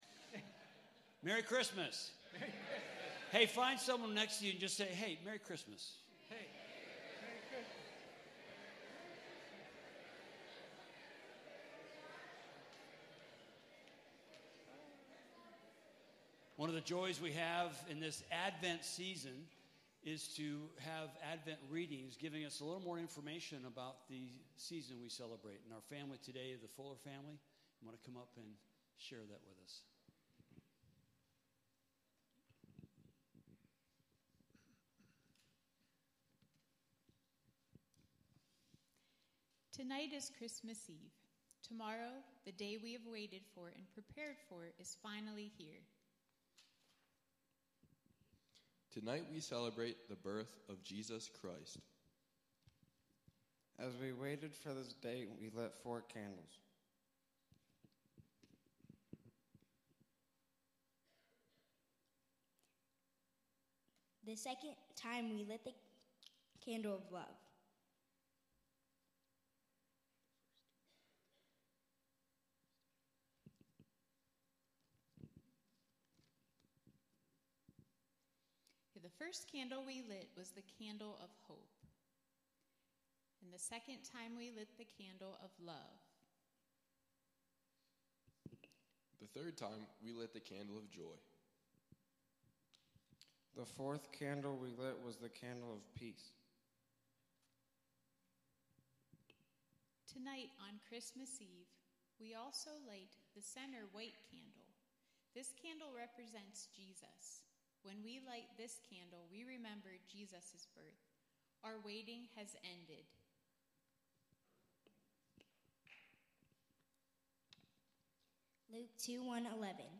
Christmas Eve Service 2025